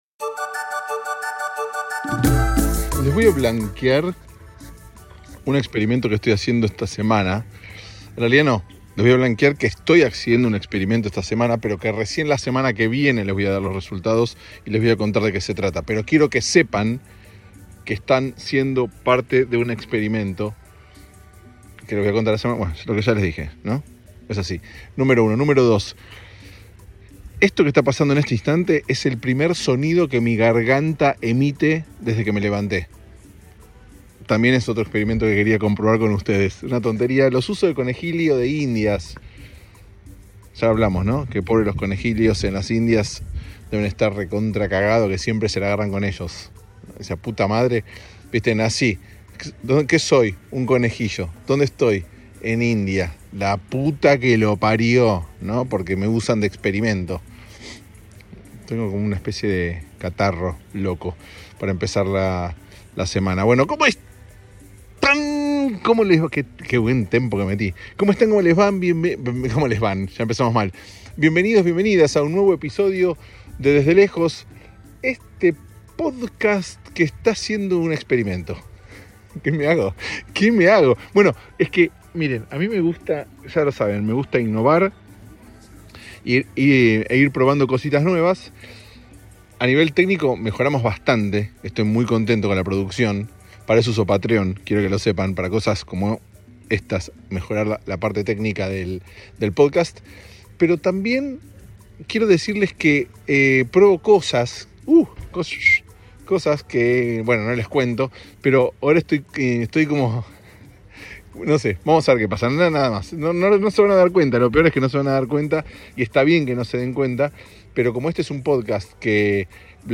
Es un episodio que incluye una canción hermosa.